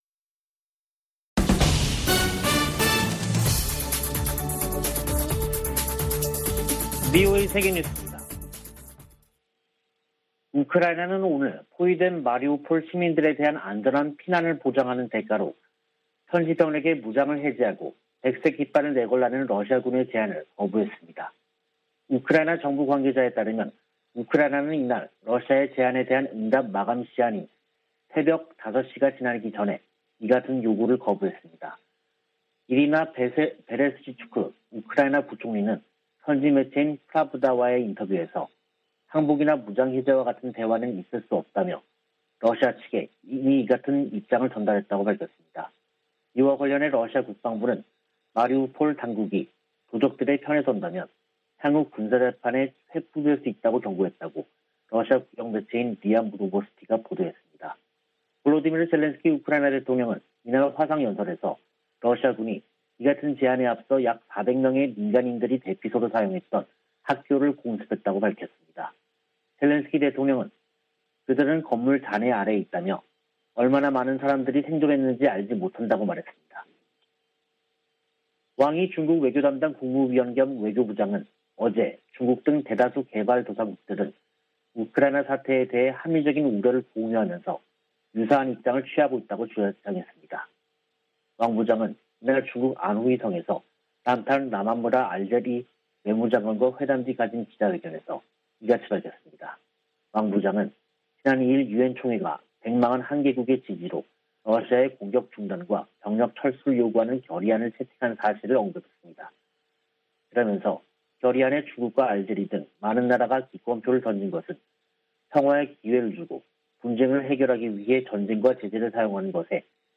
VOA 한국어 간판 뉴스 프로그램 '뉴스 투데이', 2022년 3월 21일 3부 방송입니다. 북한이 20일 한반도 서해상으로 방사포로 추정되는 단거리 발사체 4발을 쐈습니다. 미 국무부는 북한이 미사일 도발 수위를 높이는데 대해 모든 필요한 조치를 취할 것이라며 본토와 동맹국 방어 의지를 재확인했습니다. 북한이 올해도 핵과 미사일 현대화 노력을 지속하며 다양한 시험 등을 고려할 수 있다고 미 국방정보국장이 전망했습니다.